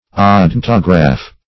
Search Result for " odontograph" : The Collaborative International Dictionary of English v.0.48: Odontograph \O*don"to*graph\, n. [Odonto- + -graph.] An instrument for marking or laying off the outlines of teeth of gear wheels.